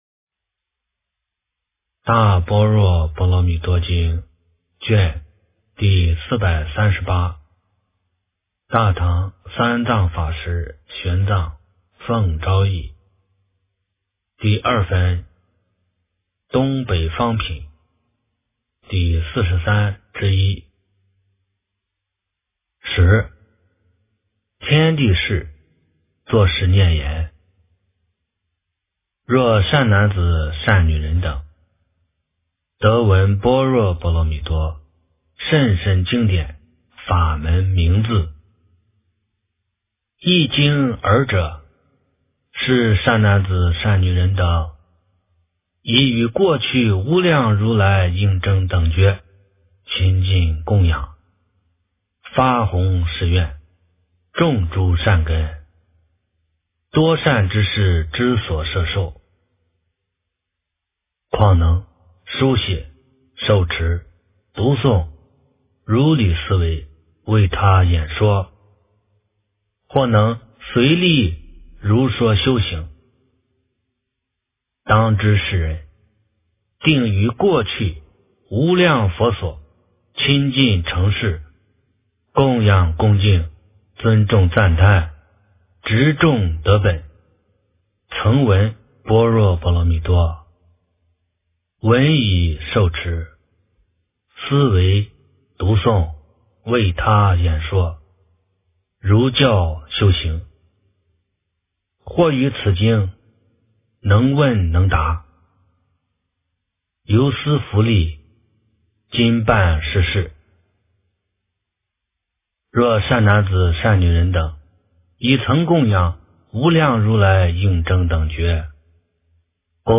大般若波罗蜜多经第438卷 - 诵经 - 云佛论坛